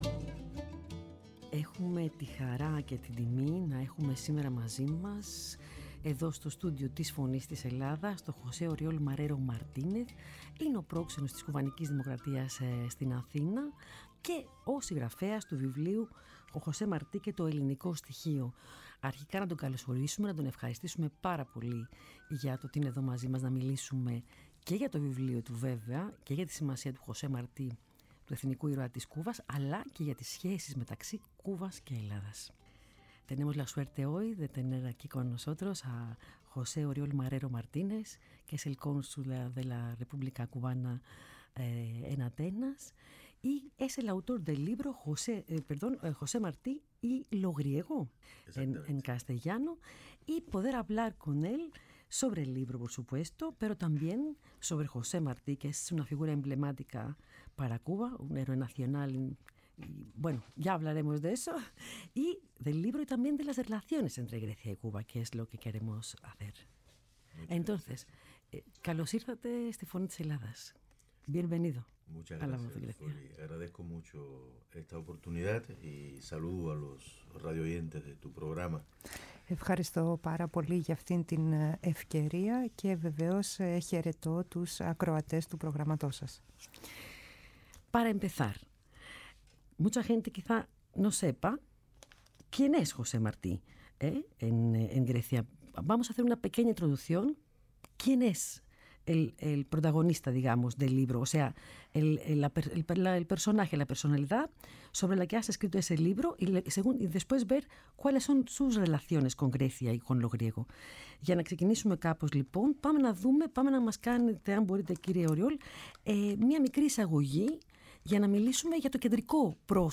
φιλοξενήθηκε στο στούντιο της Φωνής της Ελλάδας και συγκεκριμένα στην πολιτιστική εκπομπή «Αποτύπωμα»